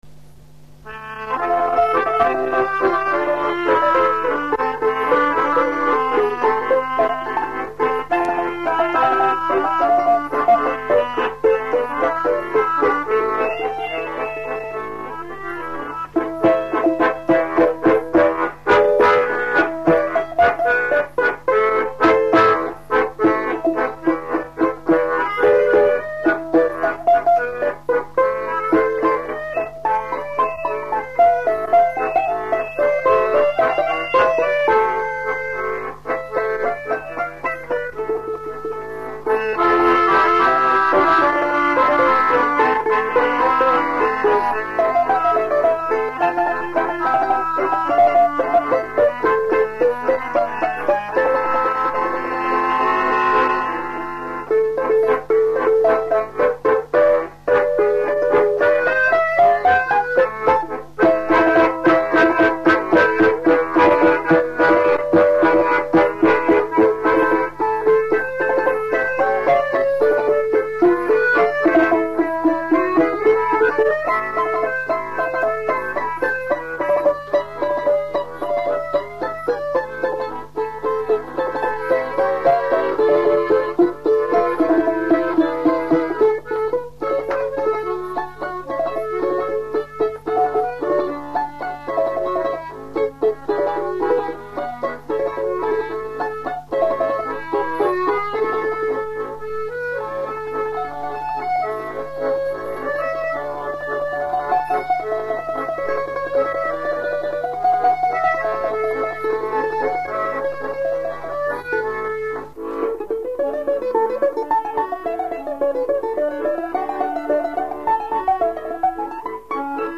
балалайка
аккордеон